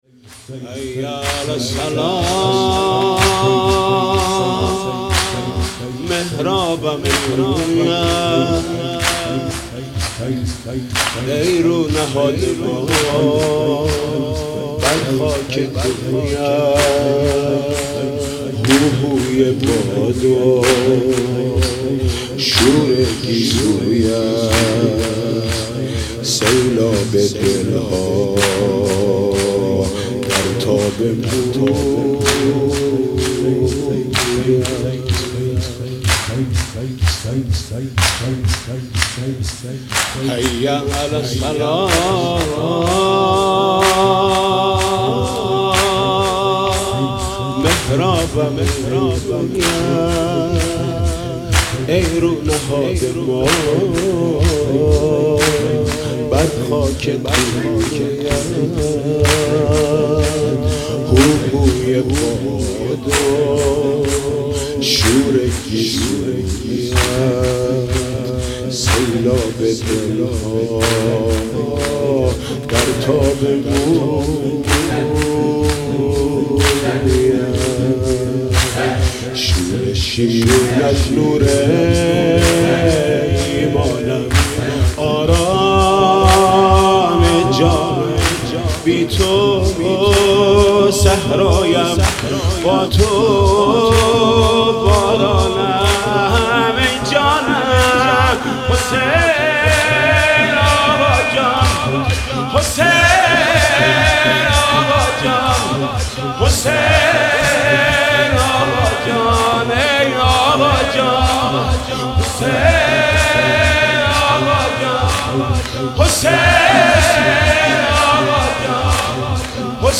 «فاطمیه 1396» شور: حی علی الصلاه محرابم رویت